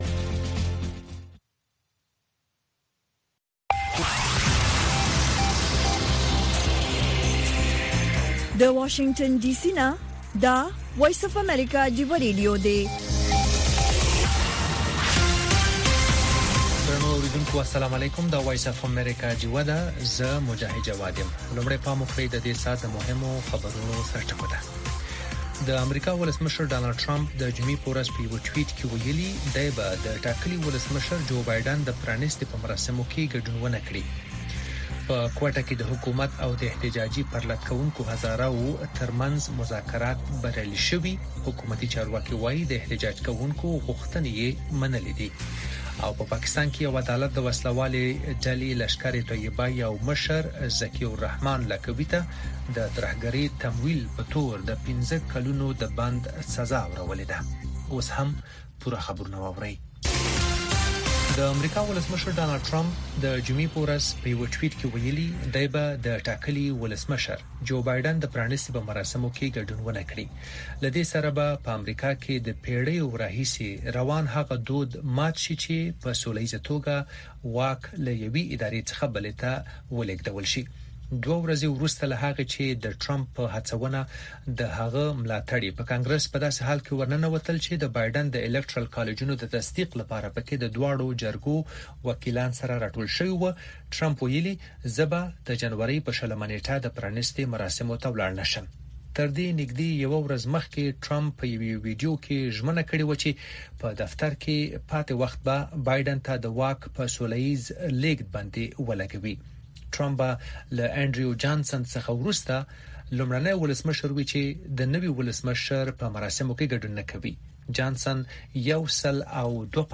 خبرونه
د وی او اې ډيوه راډيو سهرنې خبرونه چالان کړئ اؤ د ورځې دمهمو تازه خبرونو سرليکونه واورئ.